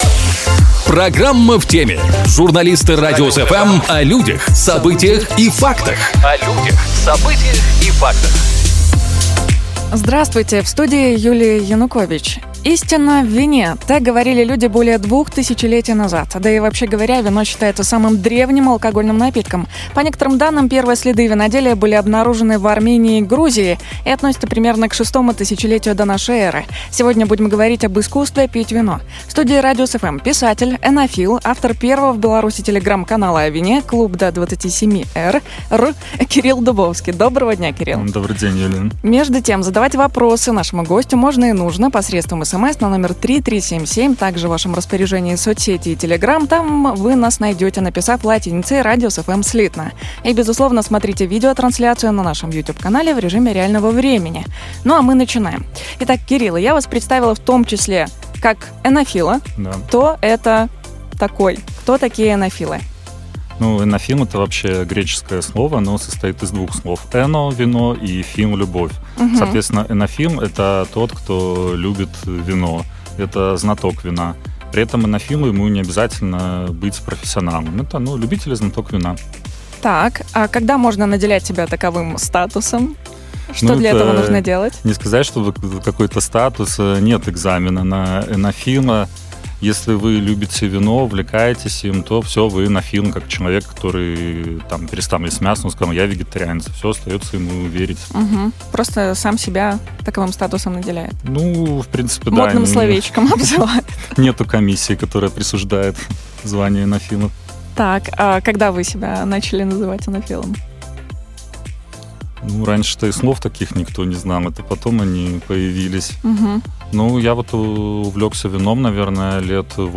В студии «Радиус FM»